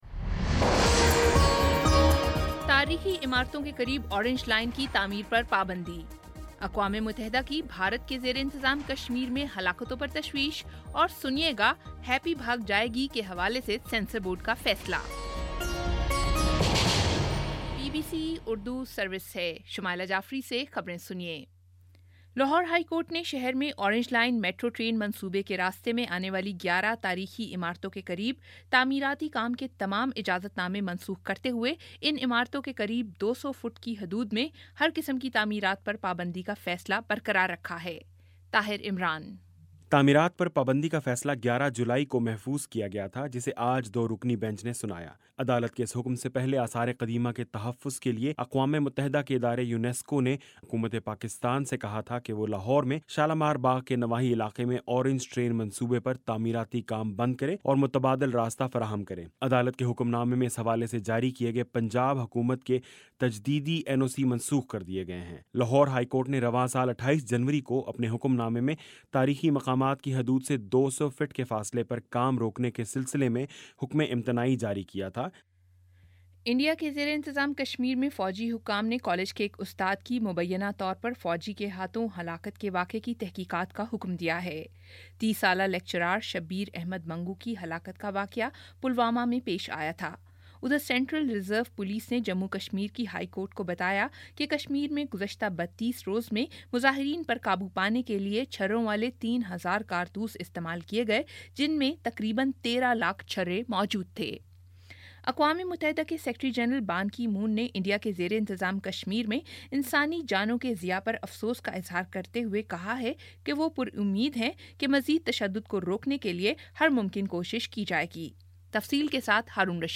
اگست 19 : شام چھ بجے کا نیوز بُلیٹن